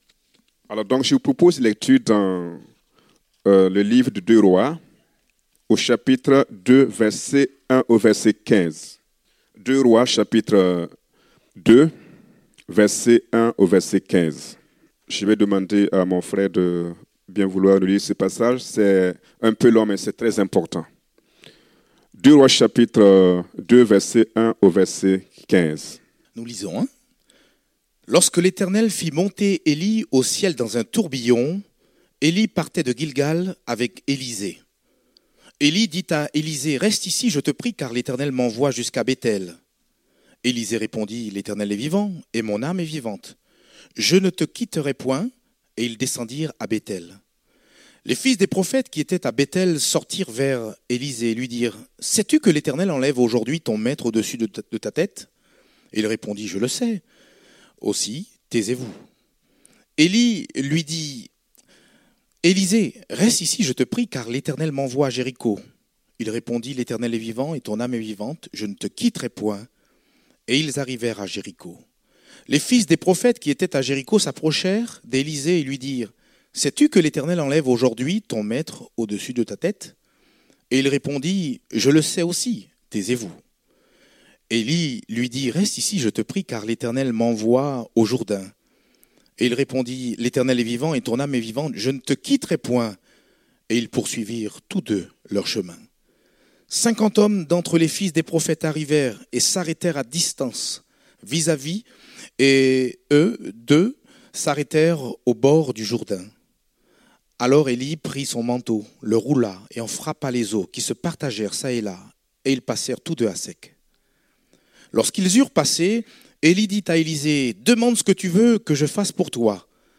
Date : 12 novembre 2017 (Culte Dominical)